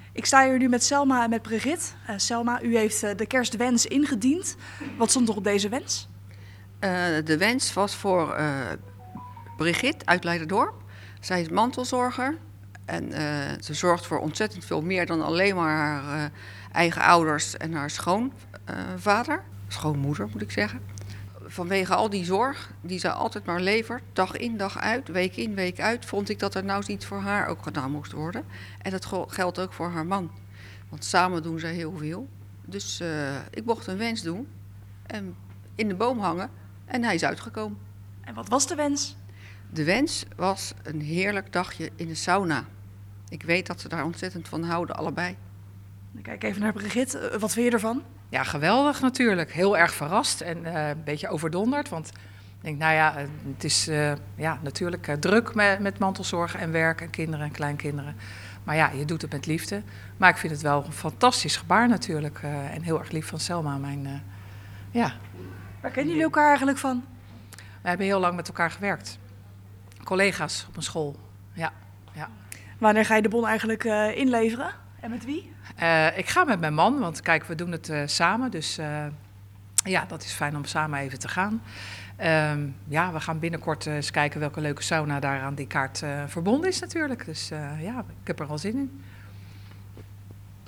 Sterrentuin